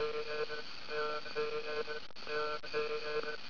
SATELLITES AND MANNED SPACESHIPS SOUNDS